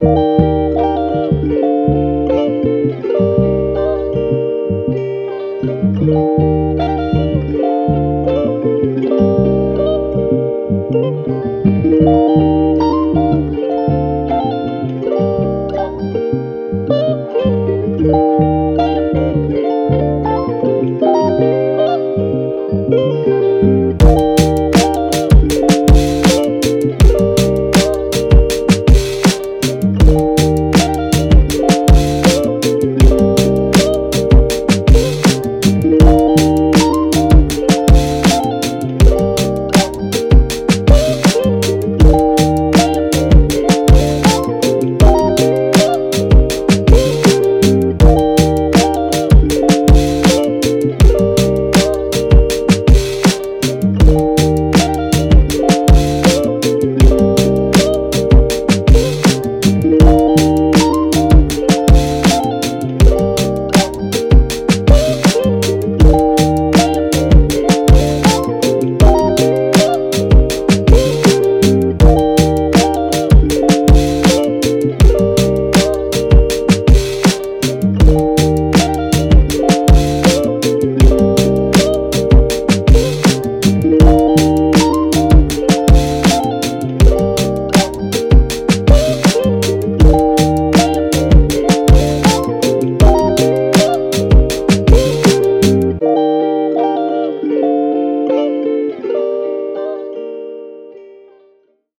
Genre: R&B and Soul
mode: calm